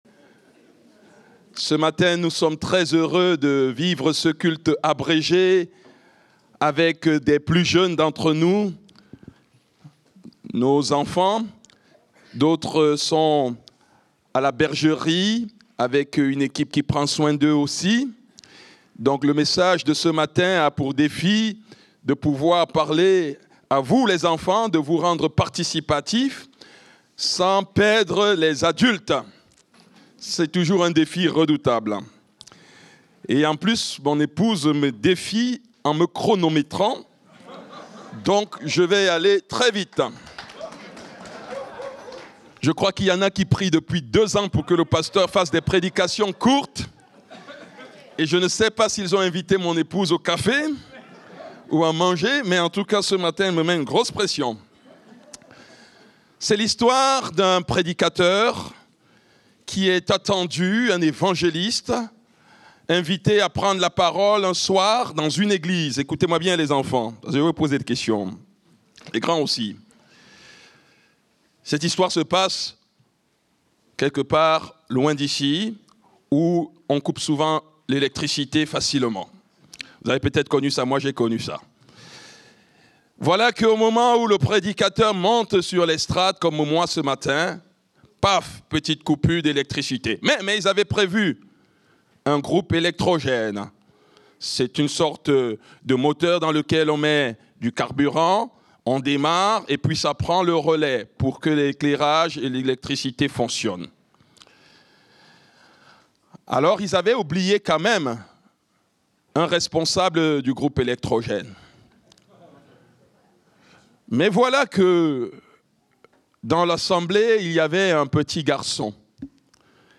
Culte du dimanche 22 juin 2025, prédication participative